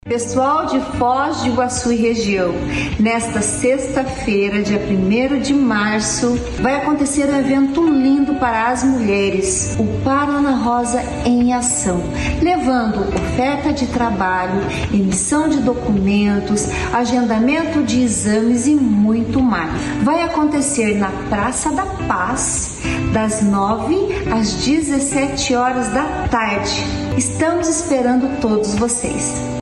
Sonora da primeira-dama do Estado